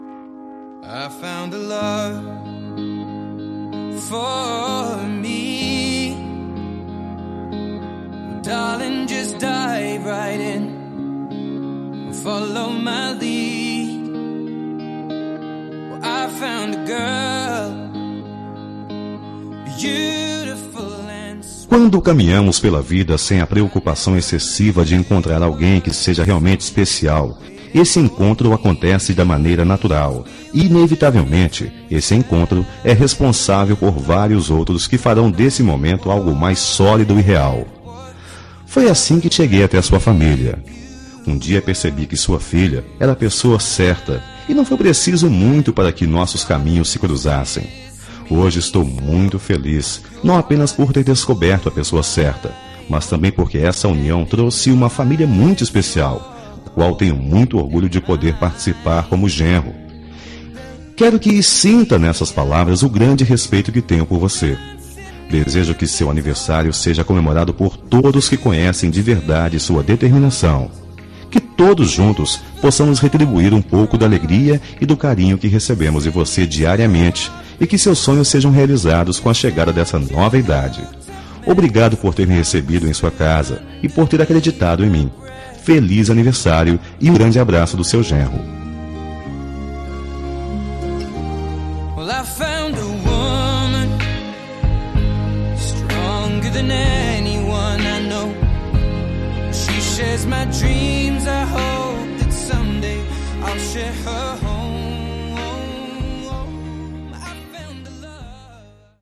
Temas com Voz Masculina
Telemensagens Aniversário de Sogra são telemensagens para ser entregue via ligação telefônica.